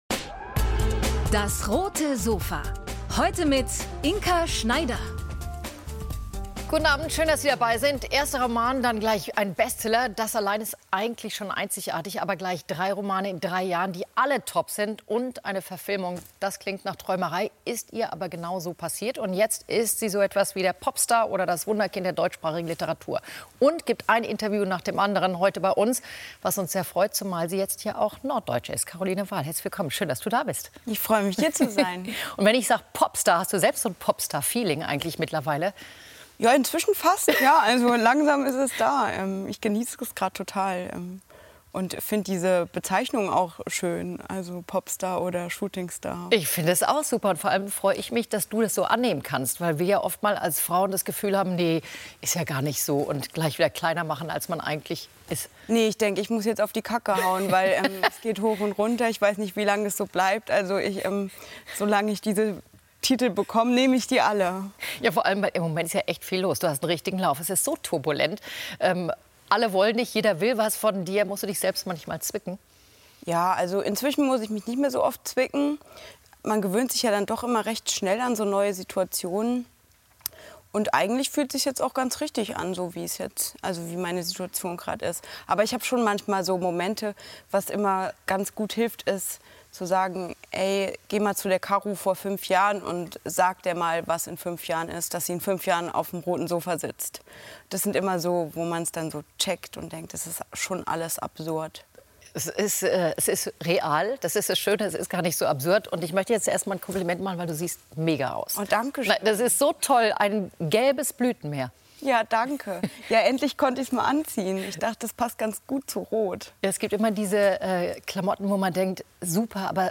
Warum sich Caroline Wahl in ihrer neuen Heimat Kiel so wohl fühlt und welche Rolle das Meer dabei spielt, erzählt sie Inka Schneider auf dem Roten Sofa.